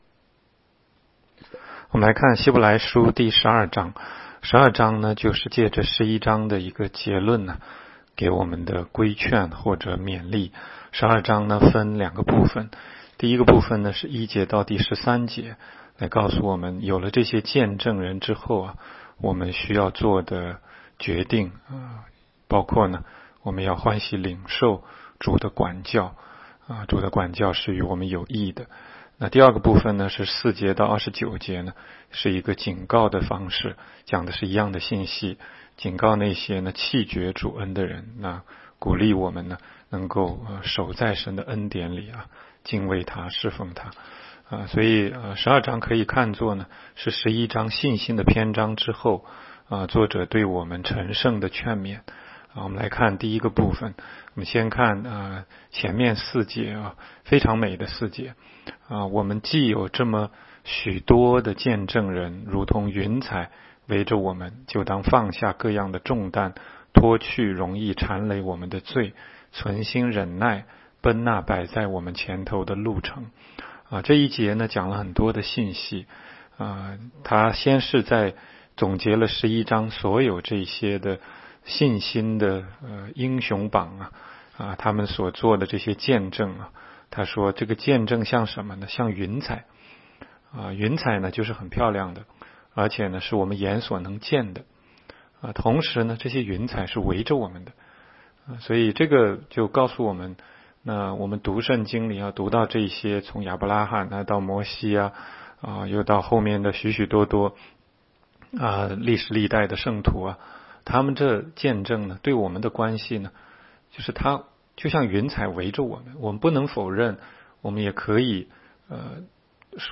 16街讲道录音 - 每日读经-《希伯来书》12章